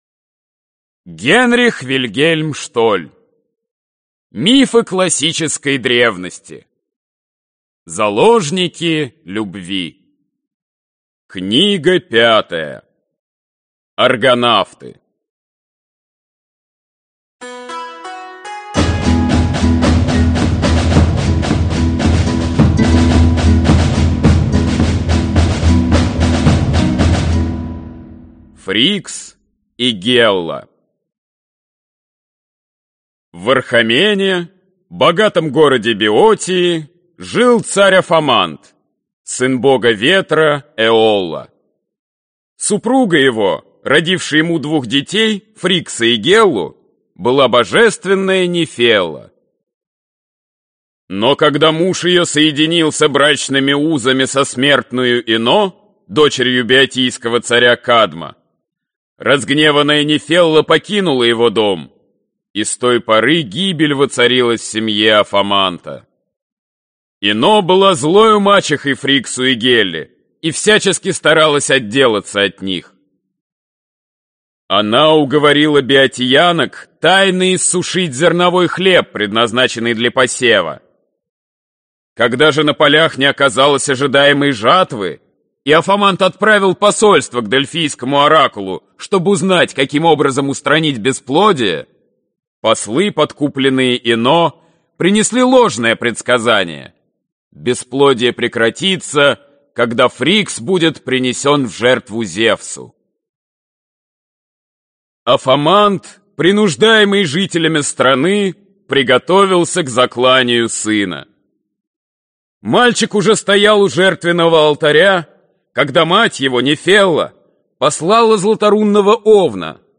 Аудиокнига Мифы классической древности. Заложники любви | Библиотека аудиокниг